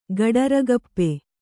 ♪ gaḍaragappe